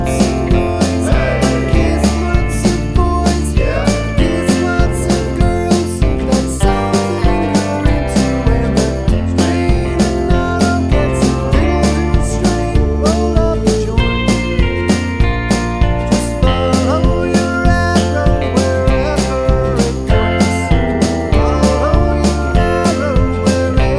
no Backing Vocals Country (Female) 3:18 Buy £1.50